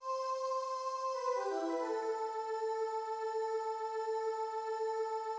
synth voice